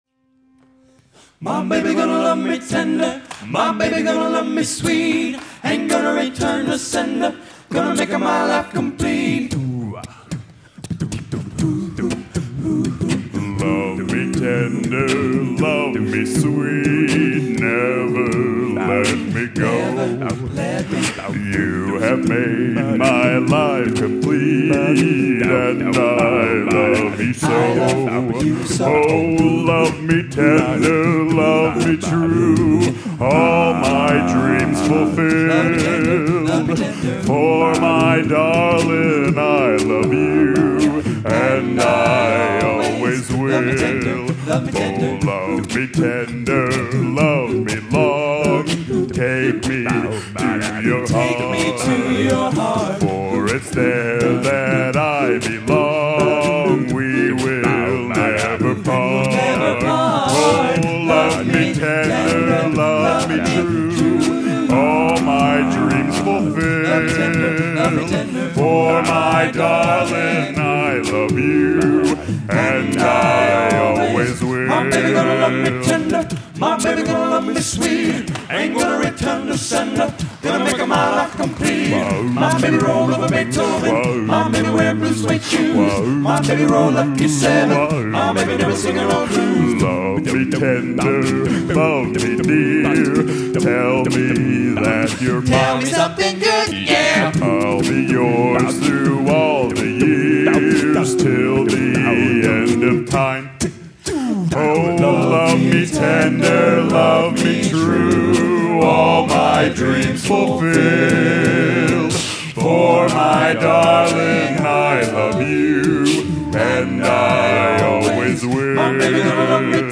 Location: Northrop High School, Fort Wayne, Indiana
Genre: | Type: Specialty